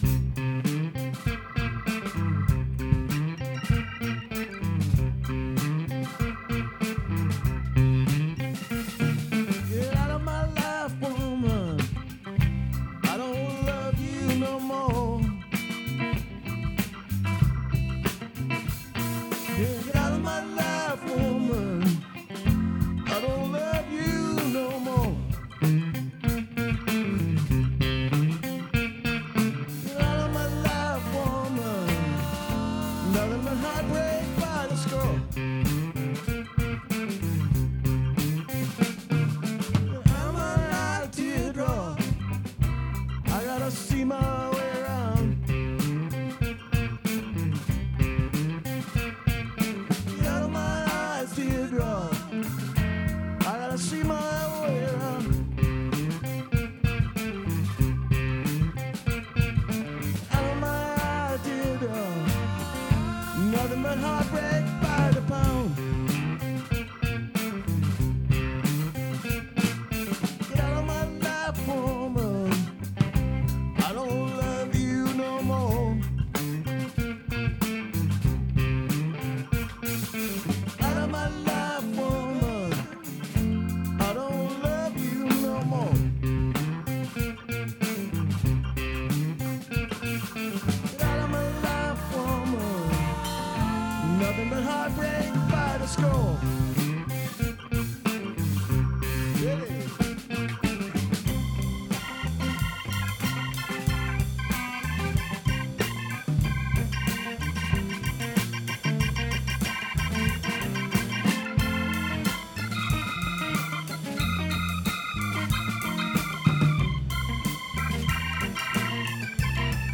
guitarist
bassist
drummer
Get Out of My Life Woman Lee Dorsey (Jerry Garcia version) Rehearsal, 4/23/2025